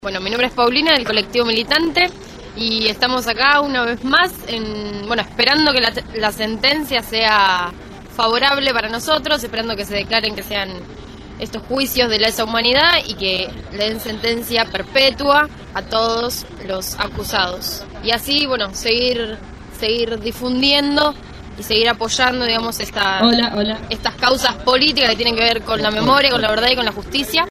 La sentencia se conoció en el centro Cultural Municipal «José Hernández», de Rawson.
Los enviados especiales de Radio Gráfica recopilaron los testimonios de los mismos.